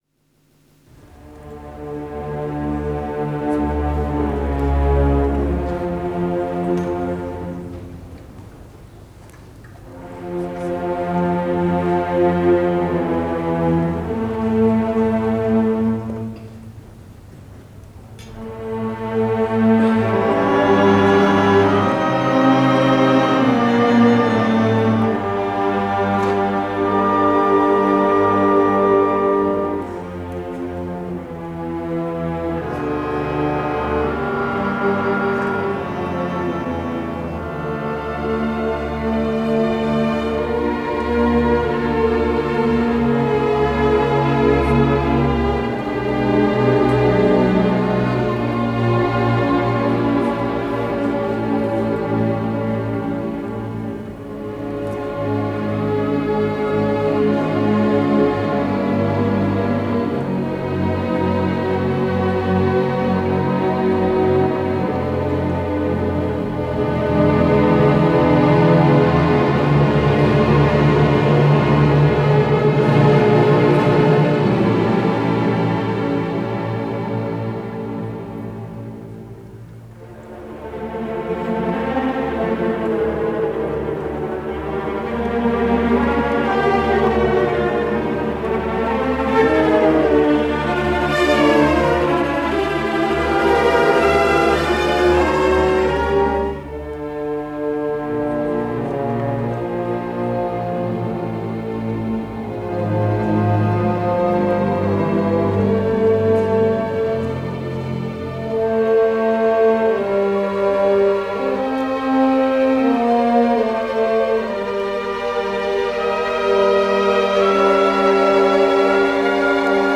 The legendary Desiré-Emile Inglebrecht leading Orchestre National de la Radioffusion Francaise in this 1955 performance of Franck’s D Minor Symphony – recorded on March 17, 1955 at Théåtre des Champs-Élysées in Paris.